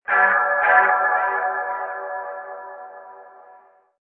descargar sonido mp3 sirena 7